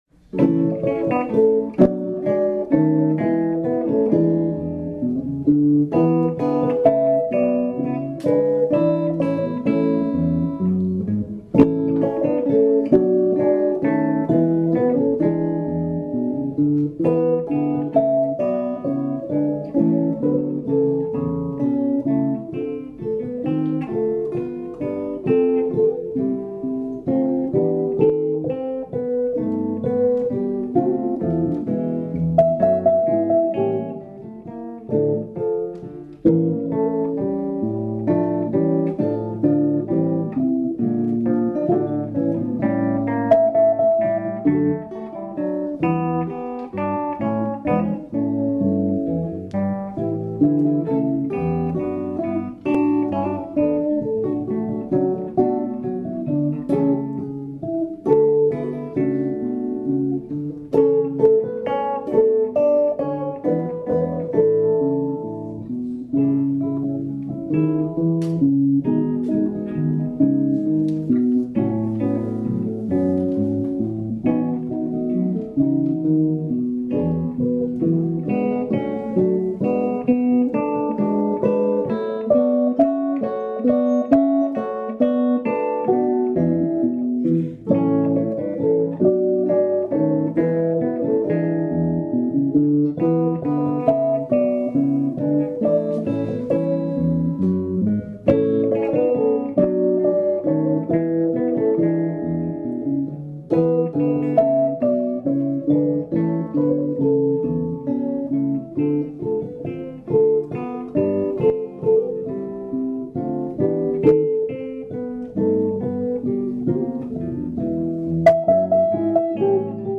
U of I Jazz Guitar Ensemble  /\
Electric Guitar